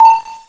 AMFMBeep.snd